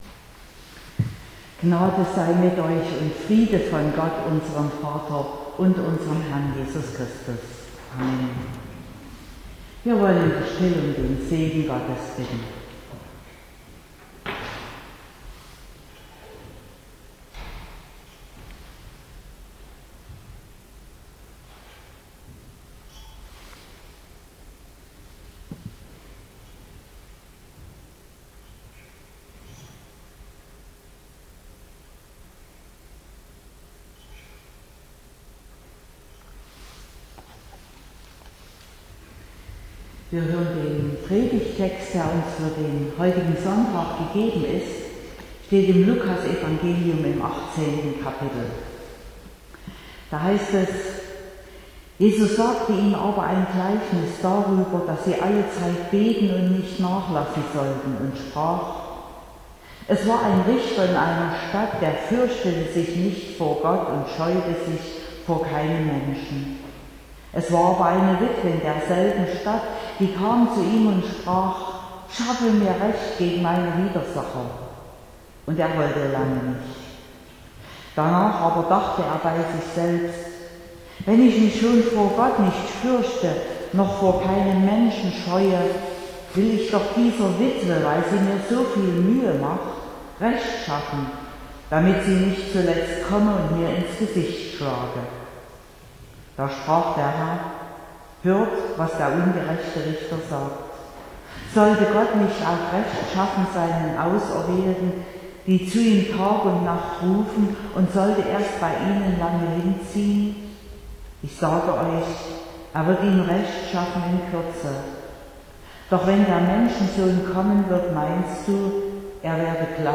13.11.2022 – Gottesdienst
Predigt und Aufzeichnungen